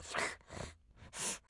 Descarga de Sonidos mp3 Gratis: snif olfato.
snif-2.mp3